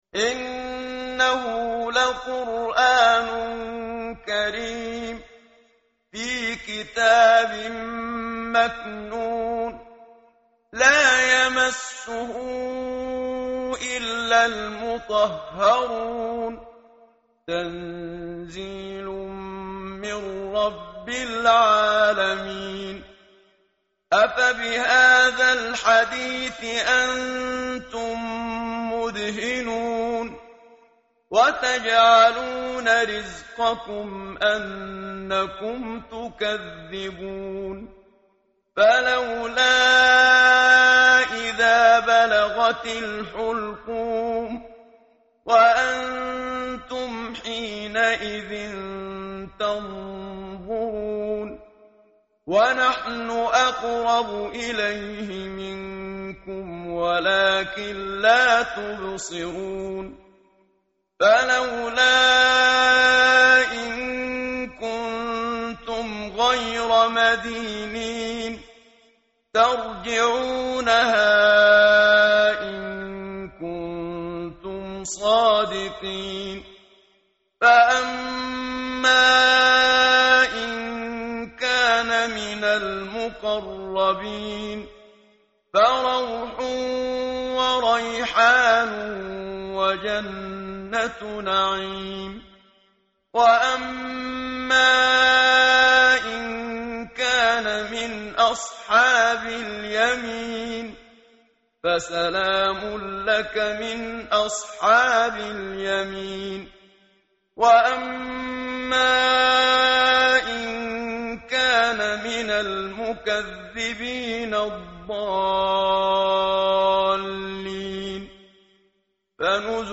tartil_menshavi_page_537.mp3